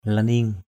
/la-ni:ŋ/ (d.) giun đất, trùn = ver de terre. earth-worm. laning ngap apan wah ln{U ZP apN wH trùn làm mồi câu cá.
laning.mp3